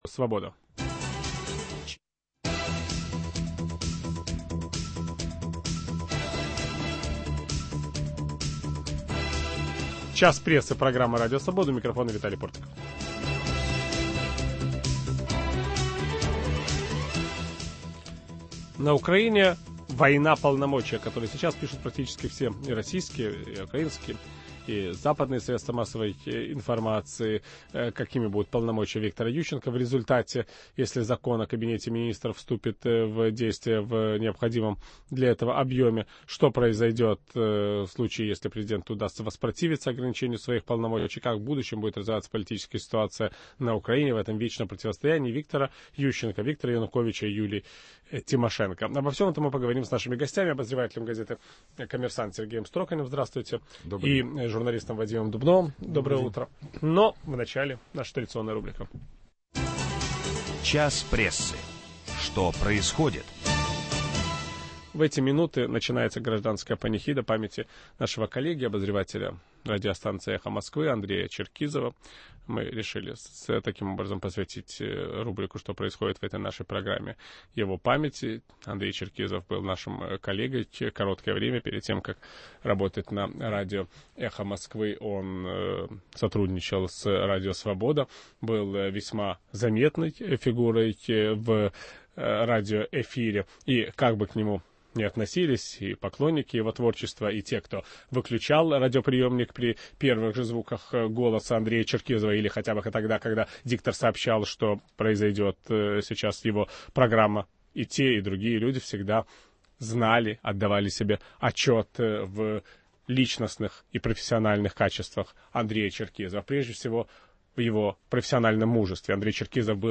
Борьба полномочий на Украине: взгляд из Москвы. В программе участвуют журналисты